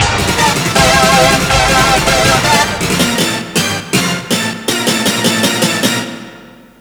chorusstab.aiff